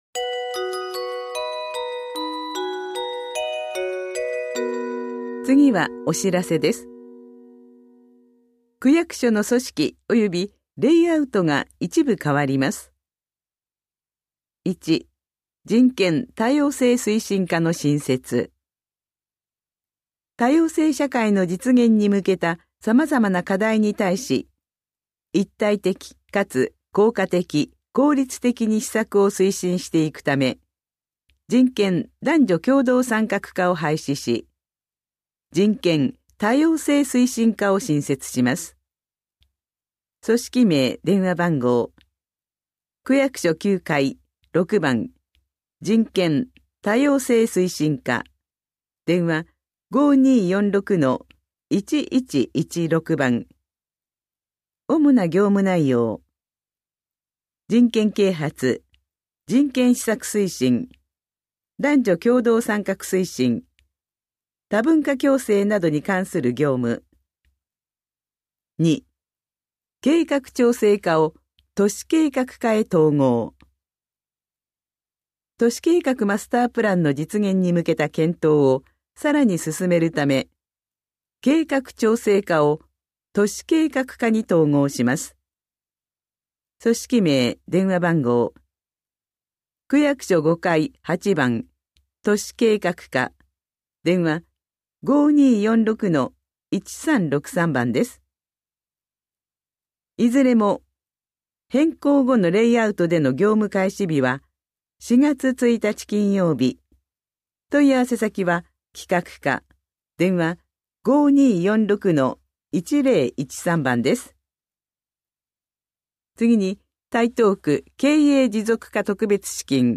広報「たいとう」令和4年3月20日号の音声読み上げデータです。